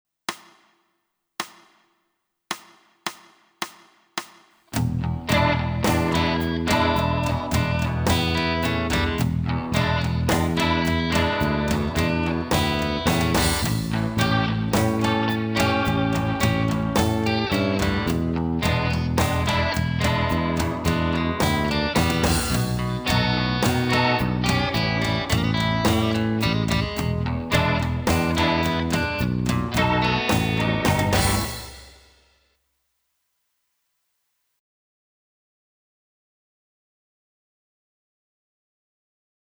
Guitare Basse